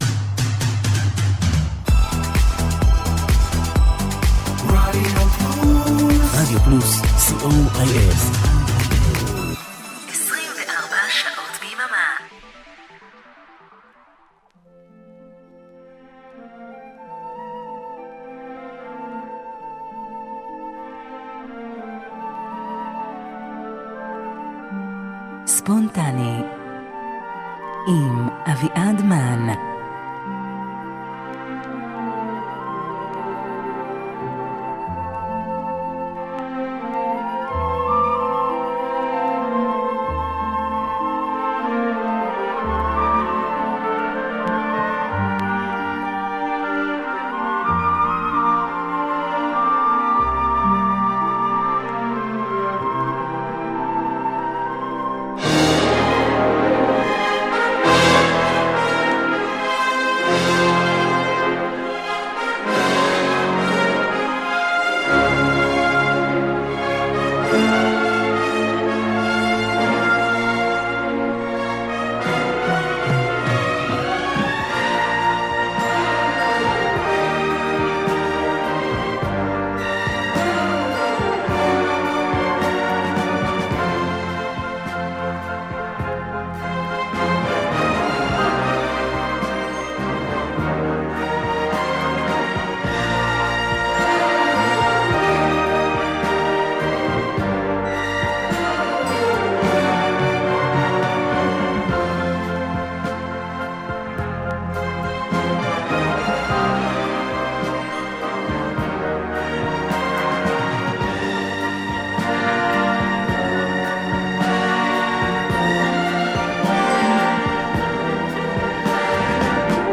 שעה רגועה של שירים לקראת שבת, שירים שנזכרתי בהם והם נזכרו בי, שירים שאולי יזכירו גם לכם משהו 🙂 האזנה נעימה.